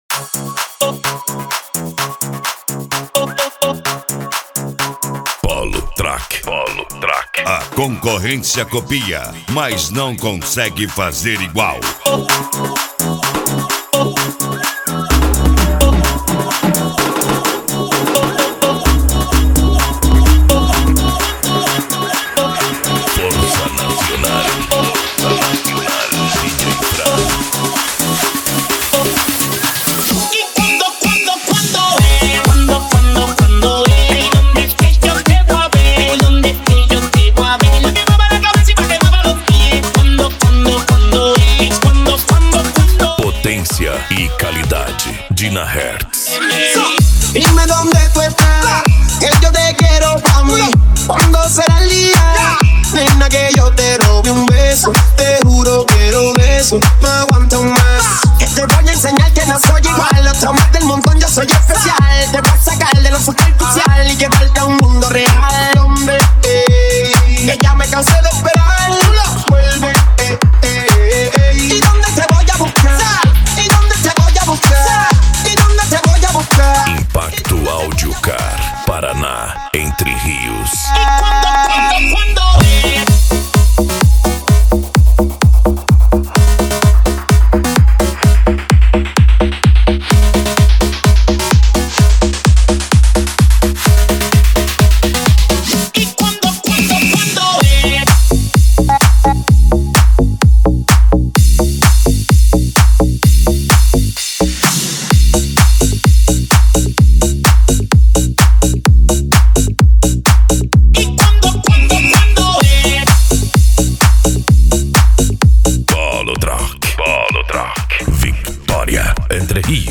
Electro House
Eletronica
Remix